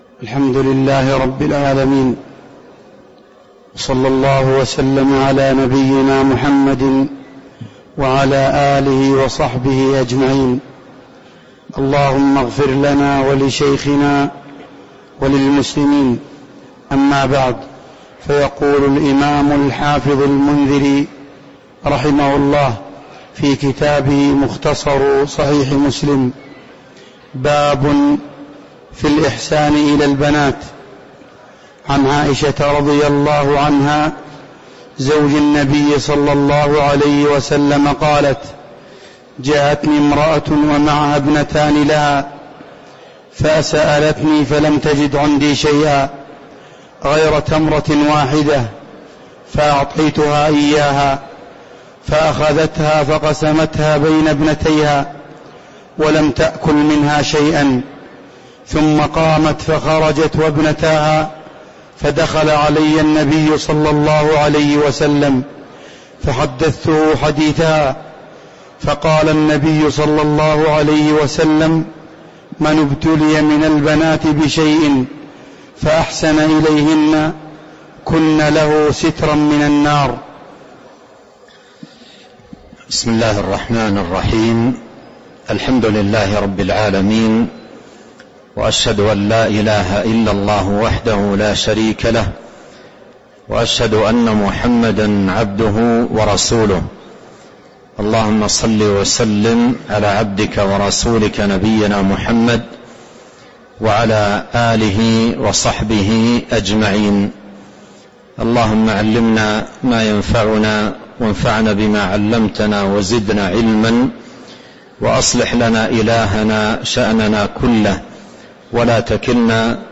تاريخ النشر ٢٣ رمضان ١٤٤٣ هـ المكان: المسجد النبوي الشيخ